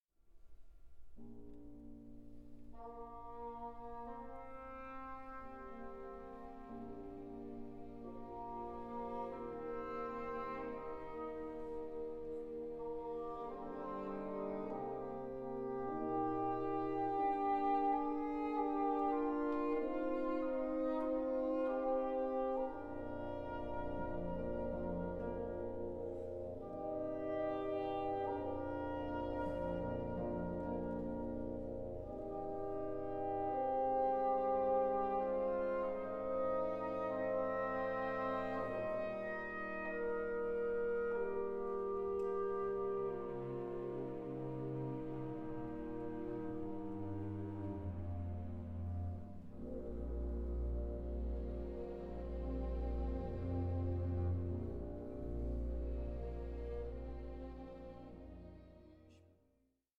Celebrating Vocal Music